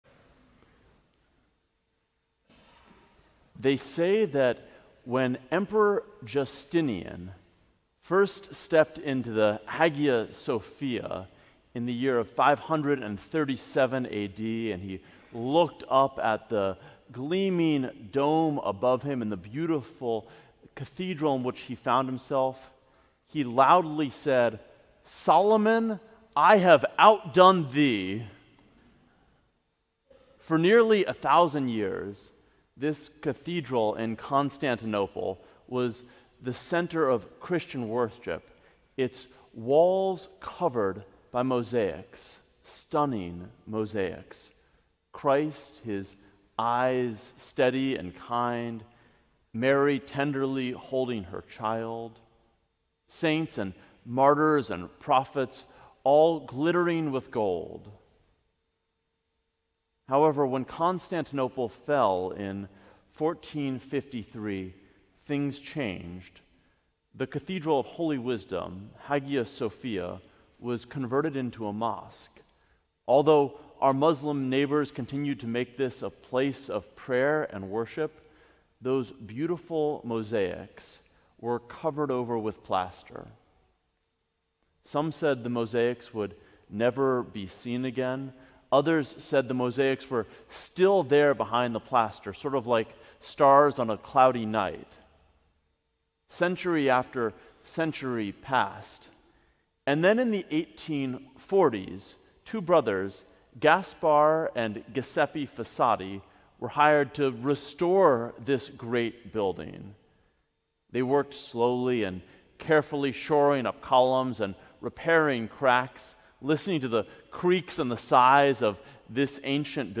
Sermon: Uncovering the Kingdom